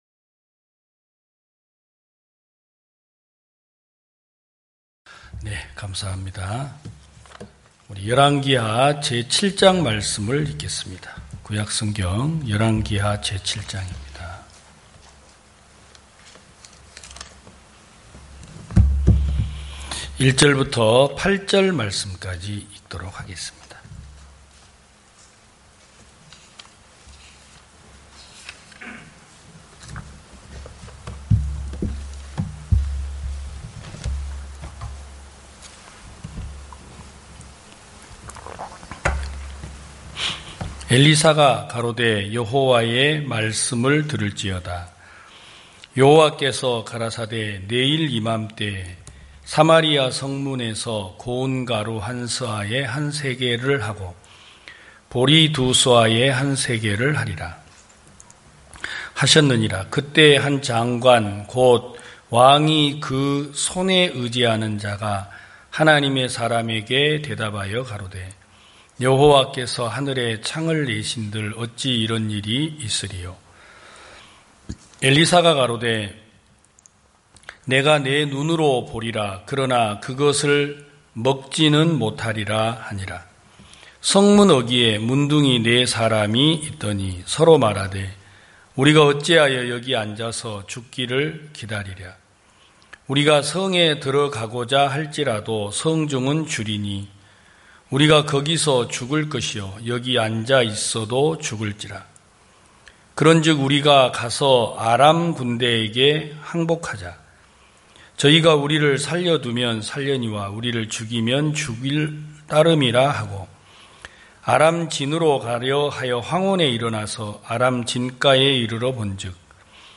2021년 9월 5일 기쁜소식부산대연교회 주일오전예배
성도들이 모두 교회에 모여 말씀을 듣는 주일 예배의 설교는, 한 주간 우리 마음을 채웠던 생각을 내려두고 하나님의 말씀으로 가득 채우는 시간입니다.